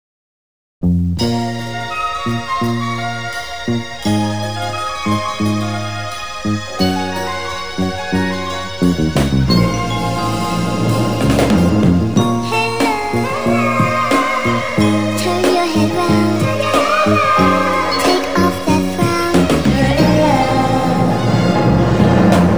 Soulful Sample .wav